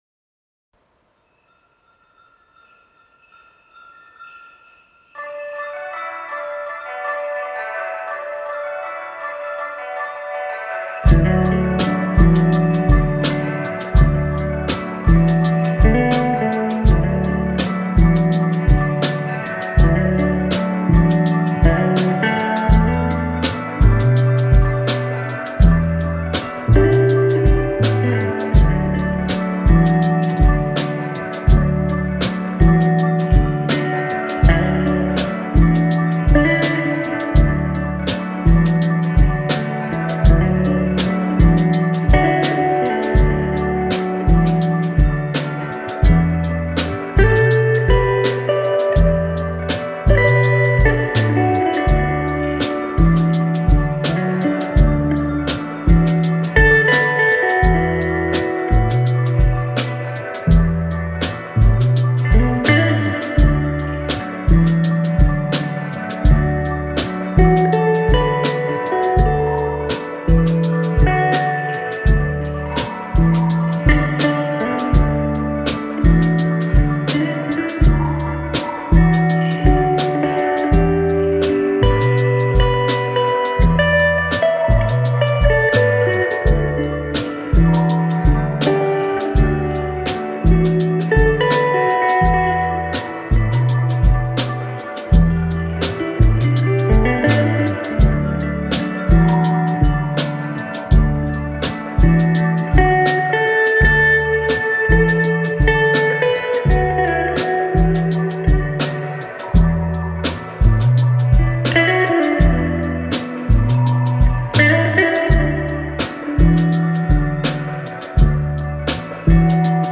Mix。